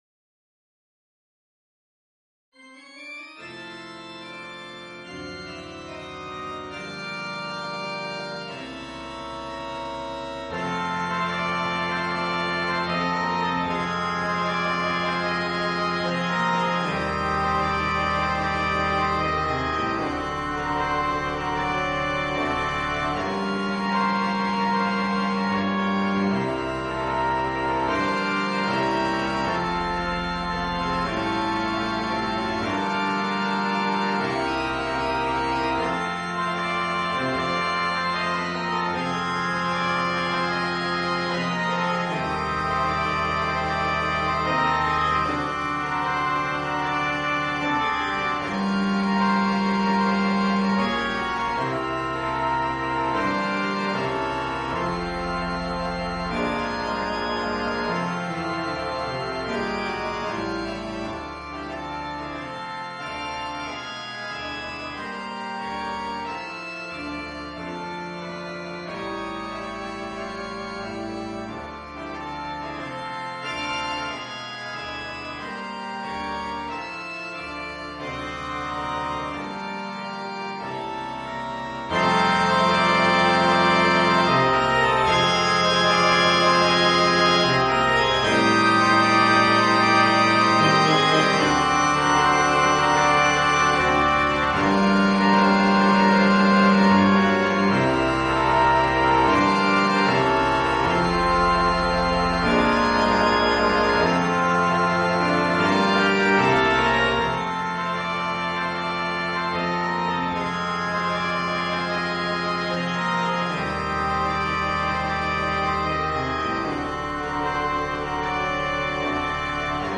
Gattung: für variables Quintett
Besetzung: Ensemble gemischt
Keyboard & Percussions optional + Play-Along CD.